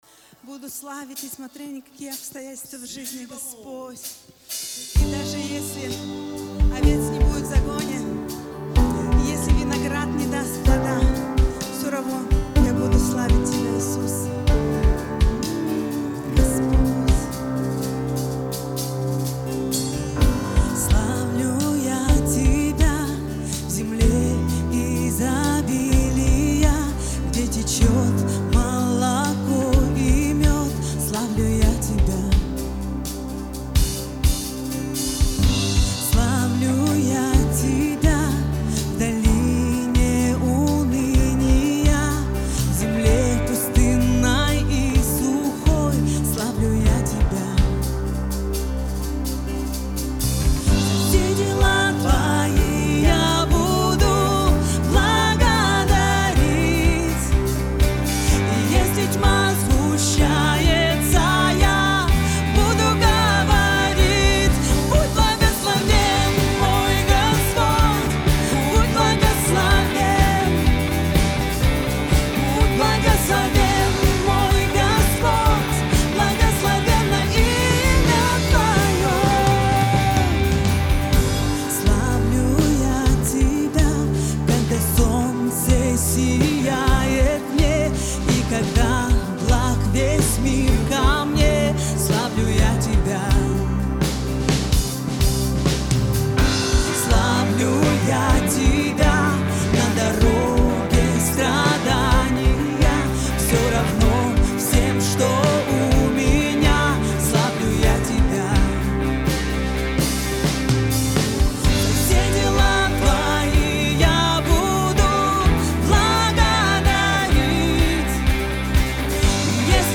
491 просмотр 193 прослушивания 21 скачиваний BPM: 128